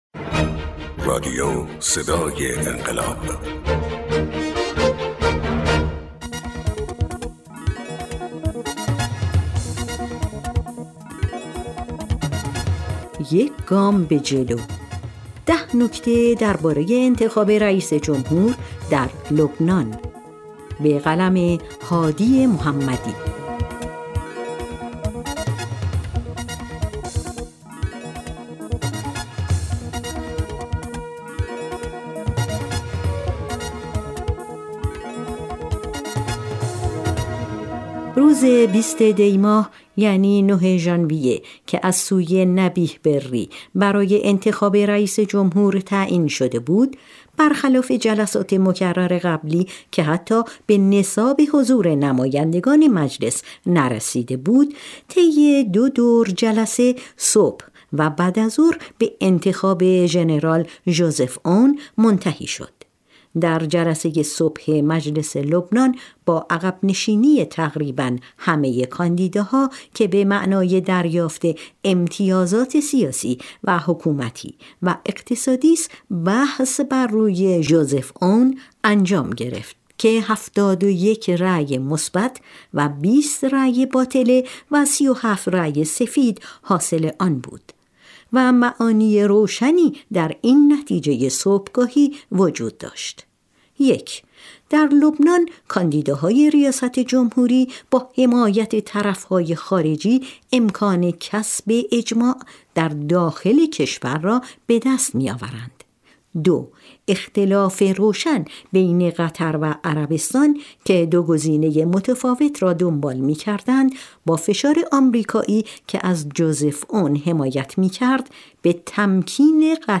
تحلیل گر منطقه و بین الملل